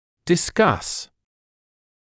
[dɪ’skʌs][ди’скас]обсуждать, дискутировать, дебатировать